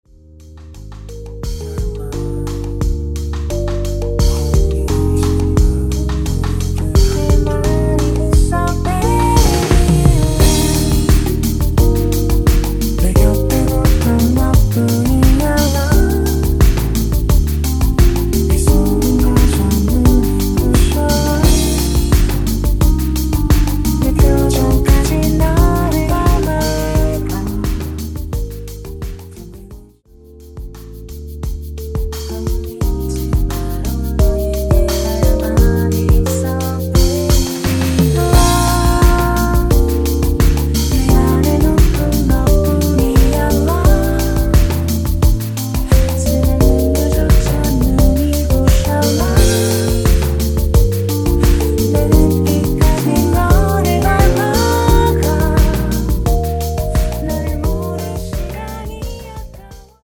엔딩이 페이드 아웃이라 노래 끝나고 바로 끝나게 엔딩을 만들어 놓았습니다.(원키 멜로디 MR 미리듣기 확인)
원키에서(-1)내린 코러스 포함된 MR입니다.
Eb
앞부분30초, 뒷부분30초씩 편집해서 올려 드리고 있습니다.
중간에 음이 끈어지고 다시 나오는 이유는